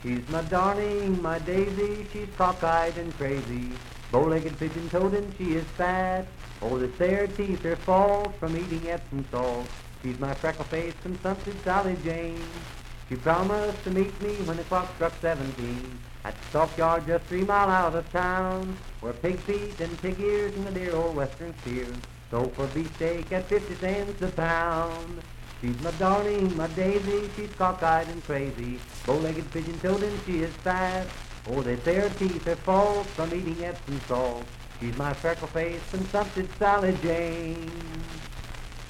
Unaccompanied vocal music
Verse-refrain 1(8) & R(8).
Voice (sung)